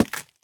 minecraft / sounds / item / axe / strip2.ogg